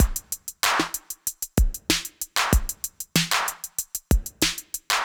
Index of /musicradar/80s-heat-samples/95bpm